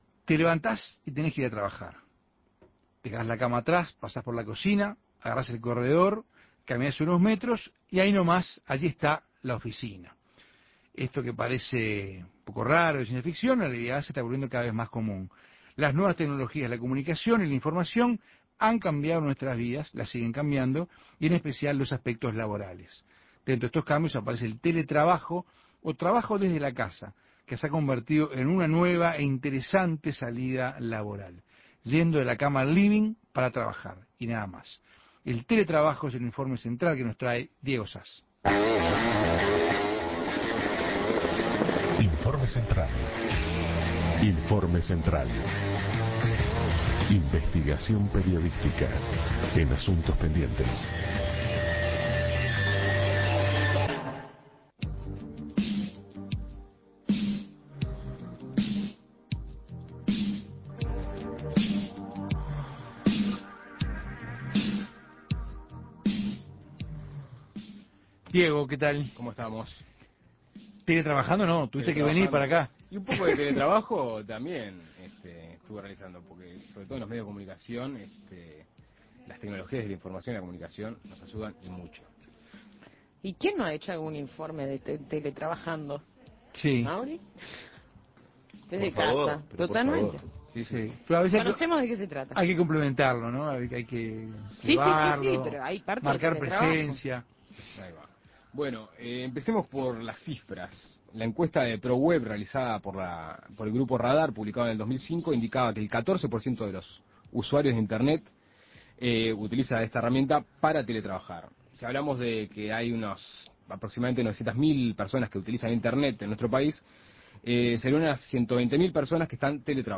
Dentro de estos cambios aparece el teletrabajo o trabajo desde la casa, que se ha convertido en una interesante salida laboral. Escuche el informe realizado por Asuntos Pendientes